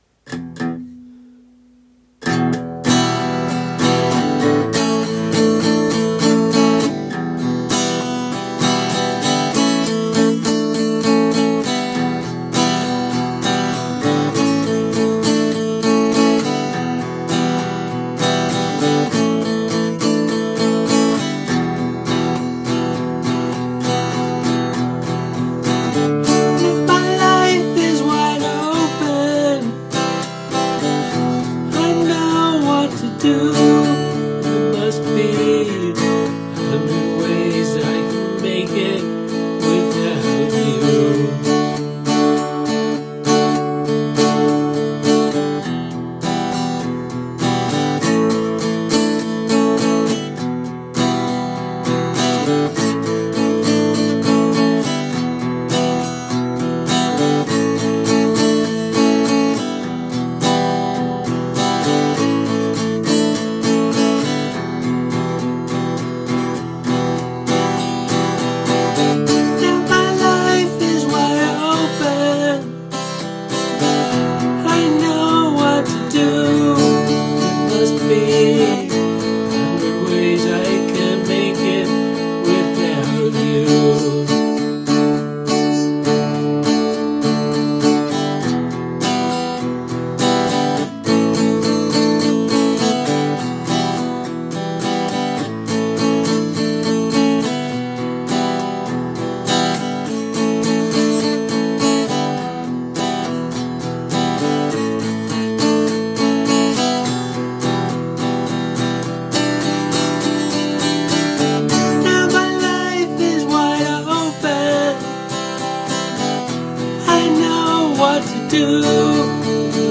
Such a catchy guitar riff.